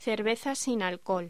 Locución: Cerveza sin alcohol
voz